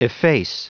Prononciation du mot efface en anglais (fichier audio)
Prononciation du mot : efface